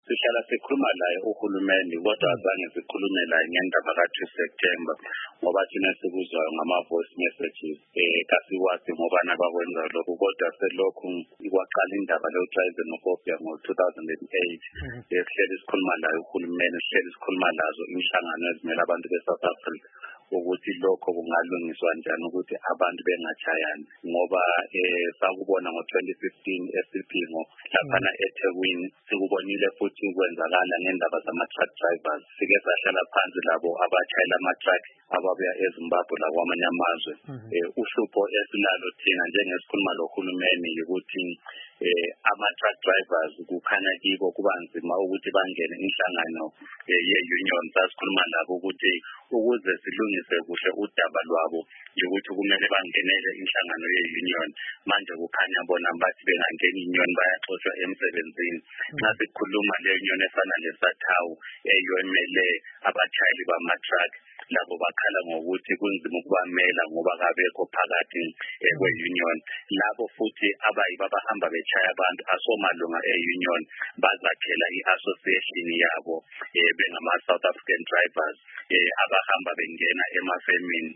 Ingxoxo Esiyenze Lodabuka Kwele South Africa